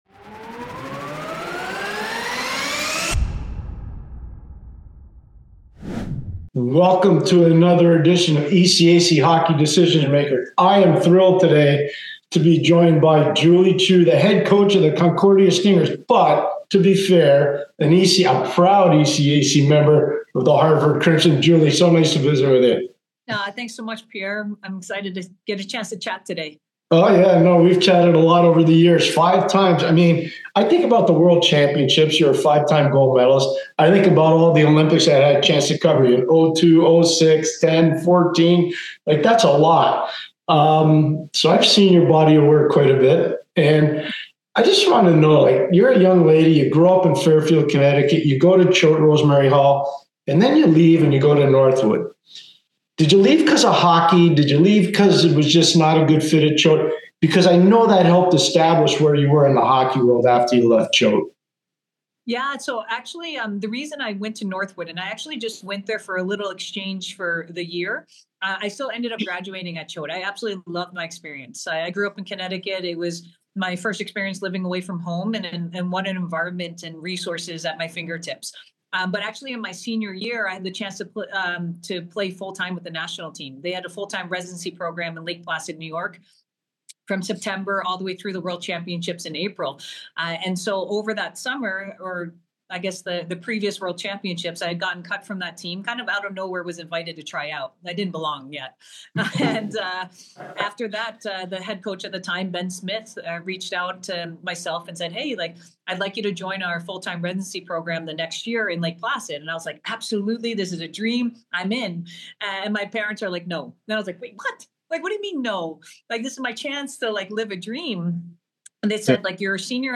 December 03, 2025 Join us for an incredible discussion on ECAC Hockey: Decision Makers with one of the most decorated players in hockey history: Julie Chu!